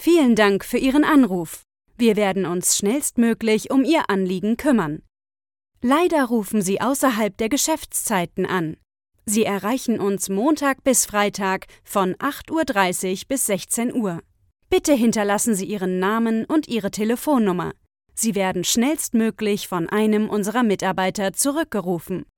Junge, Natürlich, Verspielt, Zugänglich, Freundlich
Telefonie